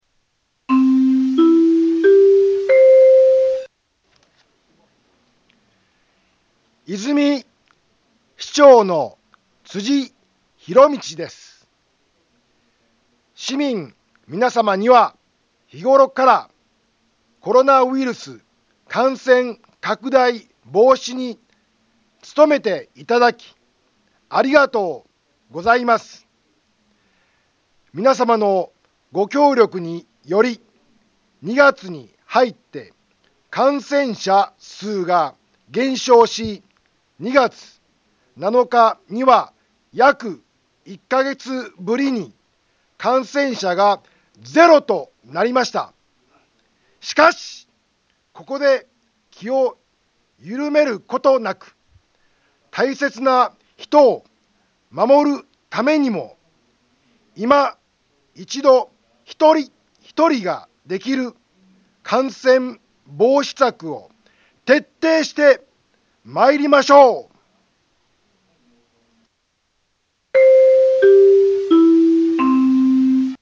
Back Home 災害情報 音声放送 再生 災害情報 カテゴリ：通常放送 住所：大阪府和泉市府中町２丁目７−５ インフォメーション：和泉市長の、辻 ひろみちです。